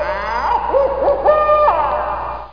hootowl.mp3